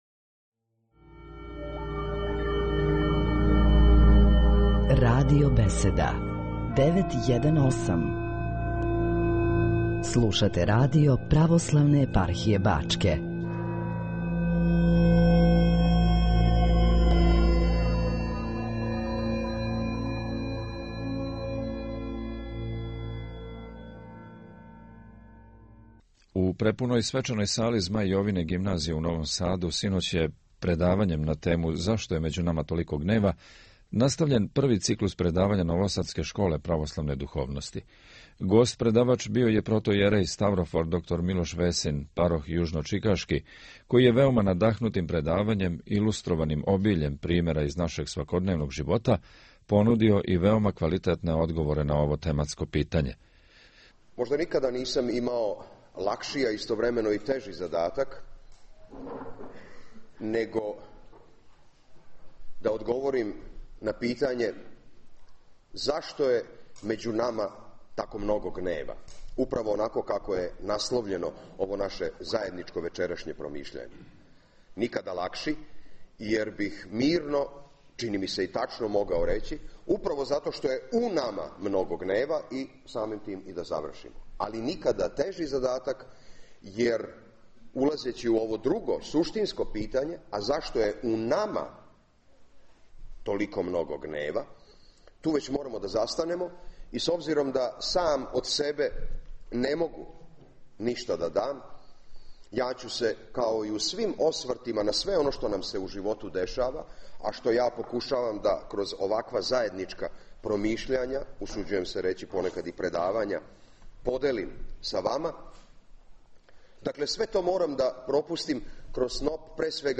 Пред мноштвом слушалаца, у свечаној дворани Змај Јовине Гимназије у Новом Саду
предавање